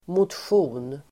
Uttal: [motsj'o:n]